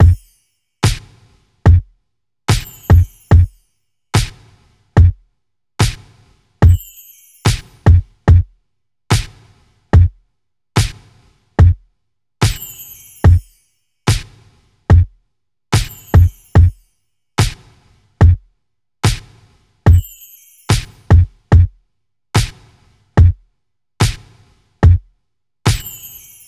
02 drums C.wav